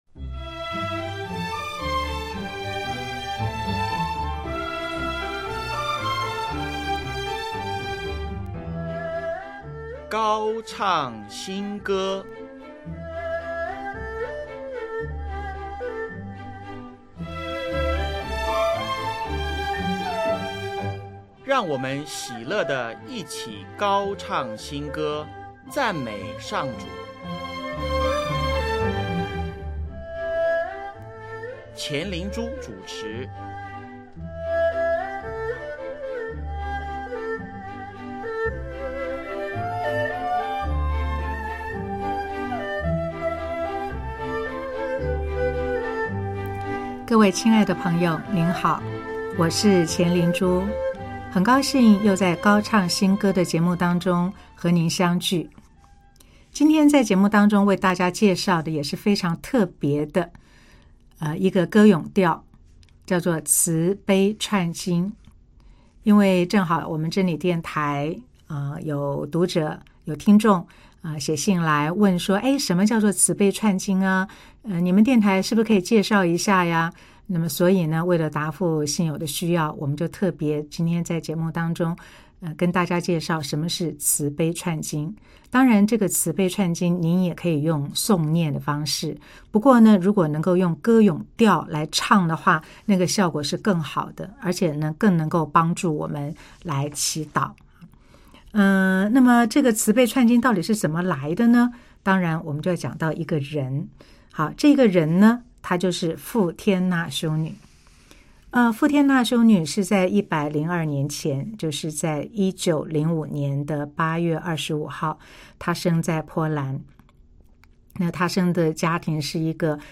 【高唱新歌】135|用歌咏调唱慈悲串经(一)：慈悲的侍者